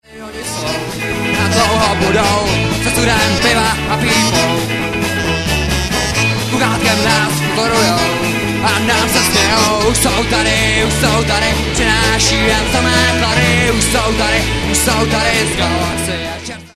Nahráno živě na koncertu v klubu Prosek dne 22.3.2002